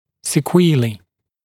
[sɪ’kwiːliː][си’куи:ли:]последствия